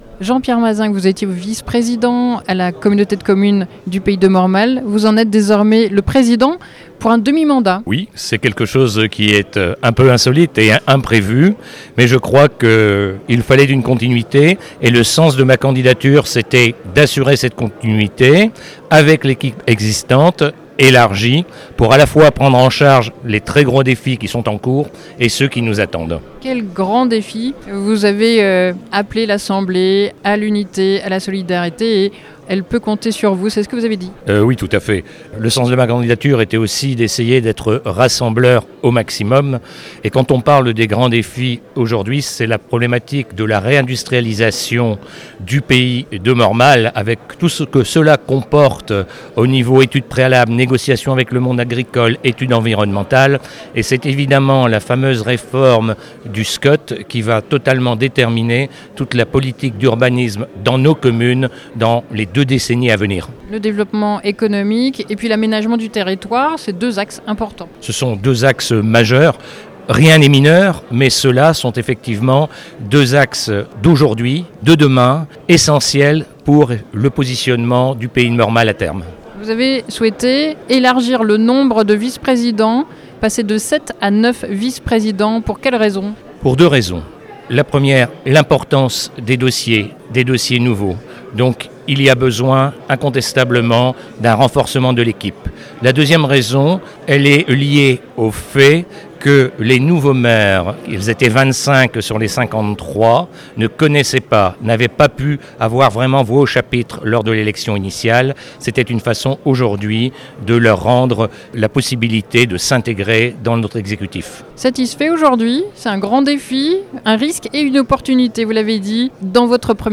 Ecoutez l’interview de Jean-Pierre Mazingue, élu président à la Communauté de communes du Pays de Mormal (50 000 habitants, 53 communes) :
Interview de Jean-Pierre Mazingue • mpeg